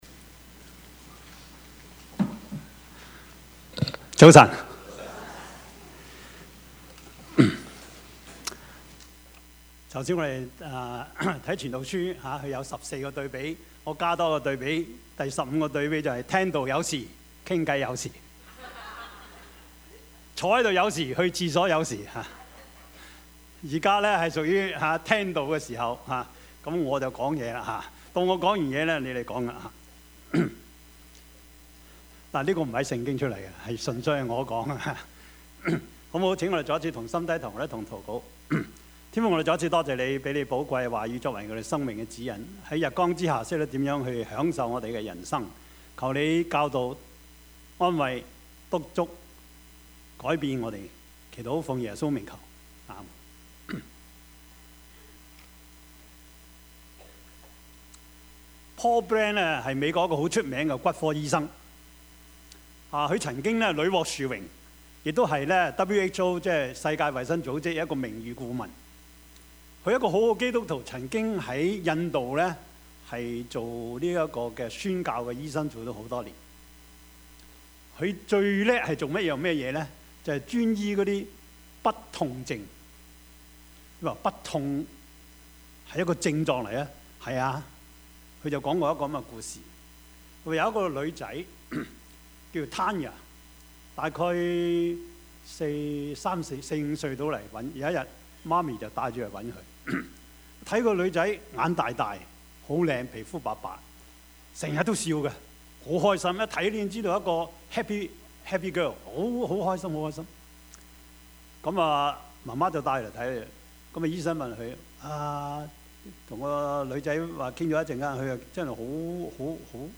Service Type: 主日崇拜
Topics: 主日證道 « 福音的愛與光 你的王來了 »